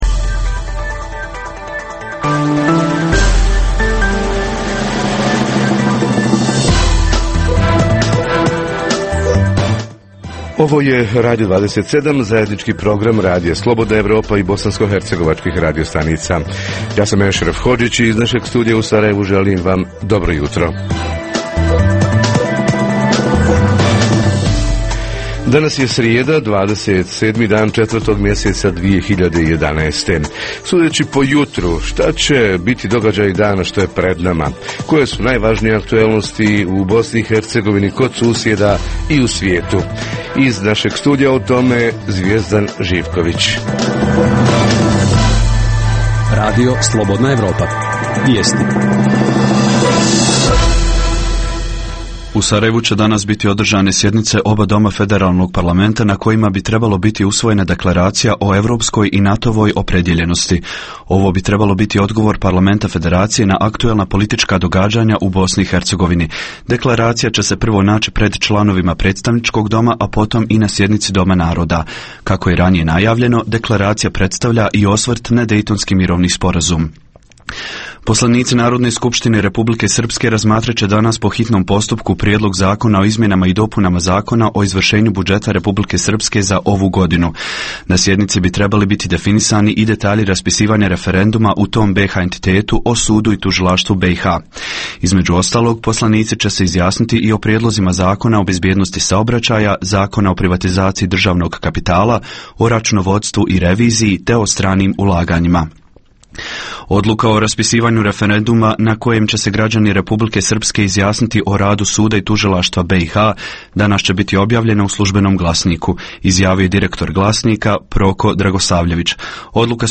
Tema jutra: plaće, penzije i druga primanja, s jedne i troškovi života, s druge strane – kako preživjeti 2011.? Reporteri iz cijele BiH javljaju o najaktuelnijim događajima u njihovim sredinama.